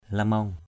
/la-mɔŋ/ (cv.)